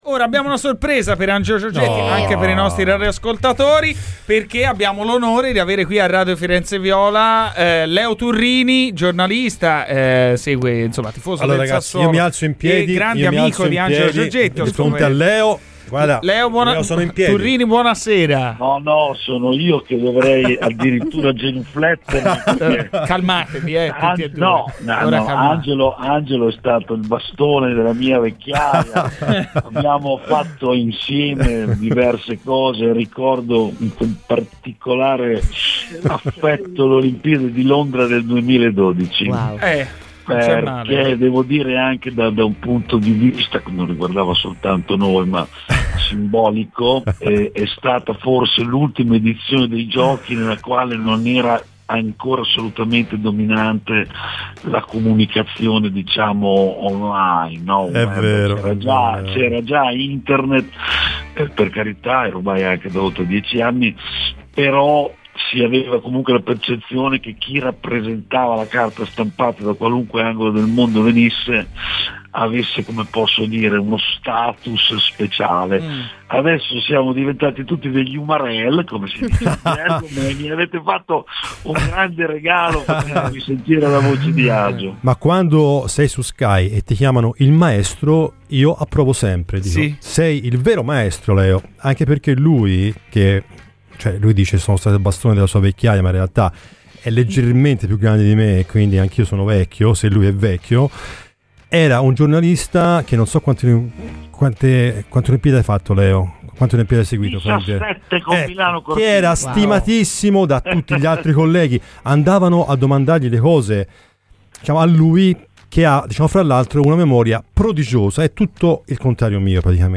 Prestigiosa firma del QS - La Nazione e noto volto di Sky Sport , soprattutto nel mondo dei motori, Leo Turrini è stato ospite di Radio FirenzeViola . Intervenuto nel corso di "Garrisca al Vento", Turrini ha fatto il punto sulla panchina della Fiorentina dell'anno prossimo.